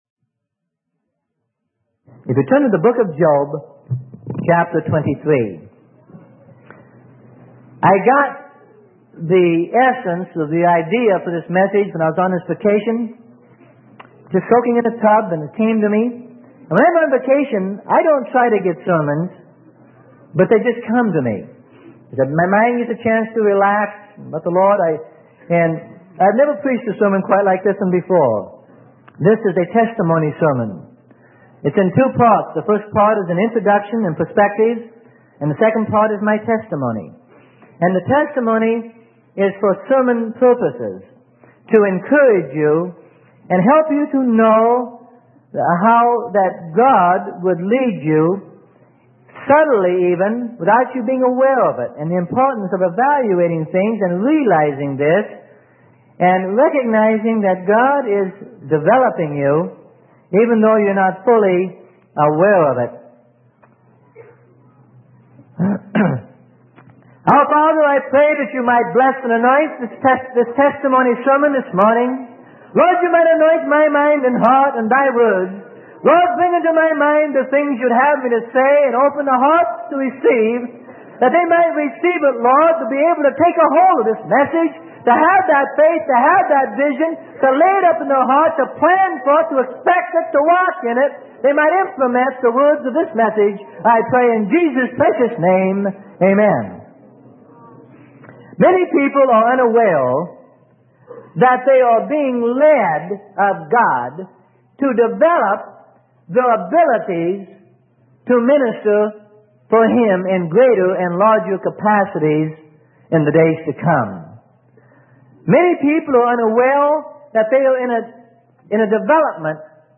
Sermon: Subtle Leading of God's Hand - Freely Given Online Library